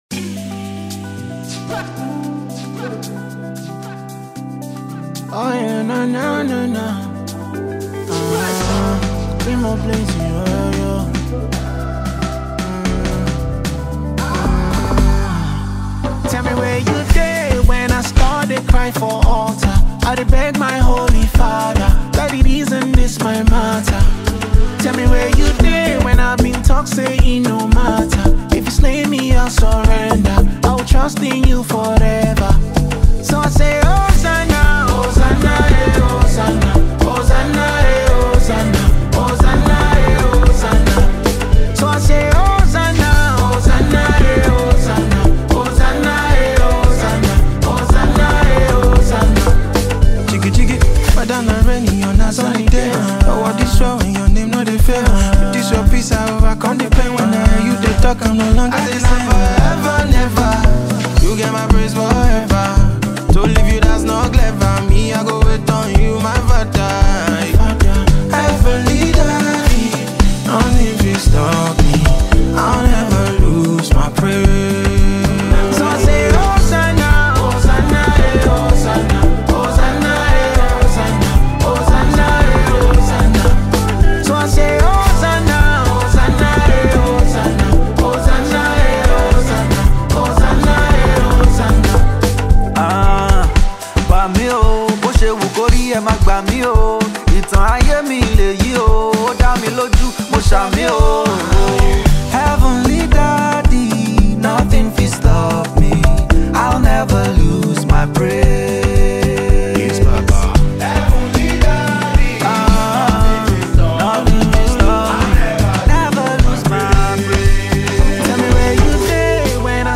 is a Nigerian gospel musician